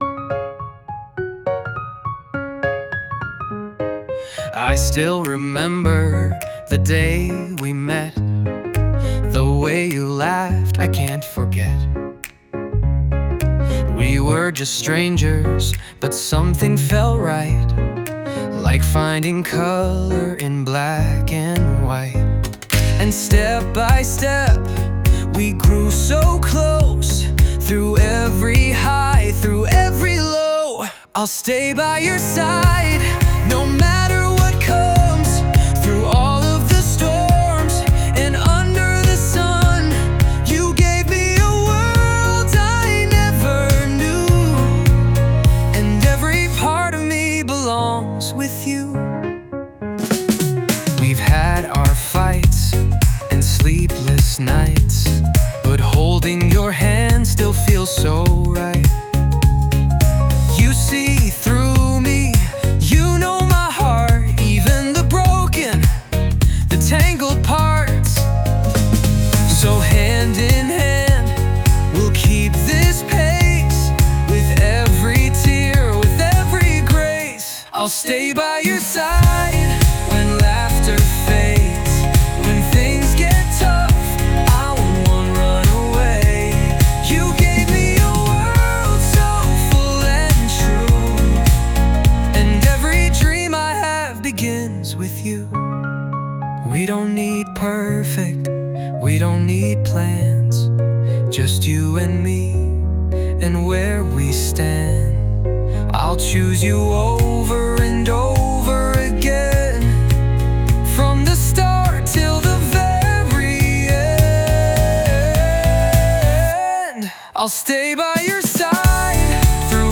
著作権フリーオリジナルBGMです。
男性ボーカル（洋楽・英語）曲です。
少しキュートで、明るく心温まるメロディに乗せて、大切な人への「ずっとそばにいたい」という想いがまっすぐに響いてきます。